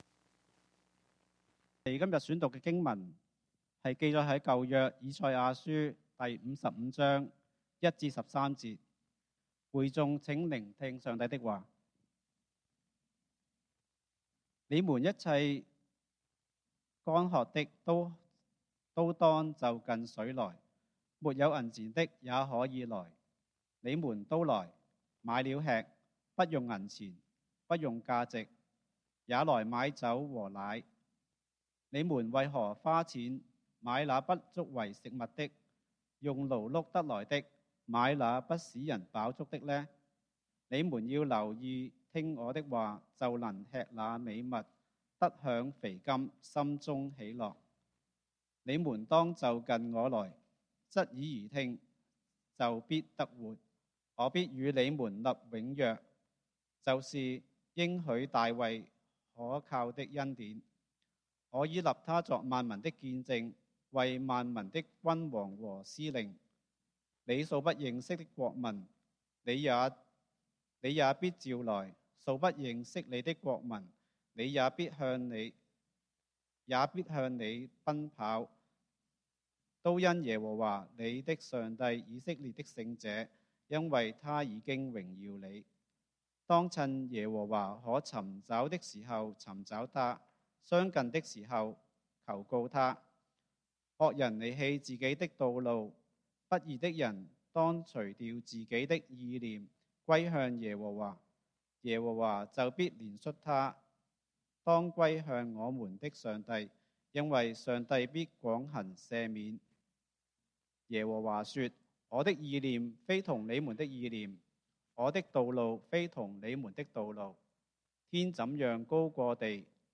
講道經文：以賽亞書 Isaiah 55:1-13